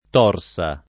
Torsa [ t 0 r S a ]